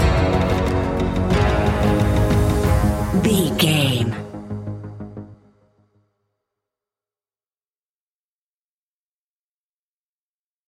Aeolian/Minor
G#
ominous
suspense
eerie
drums
synthesiser
ticking
electronic music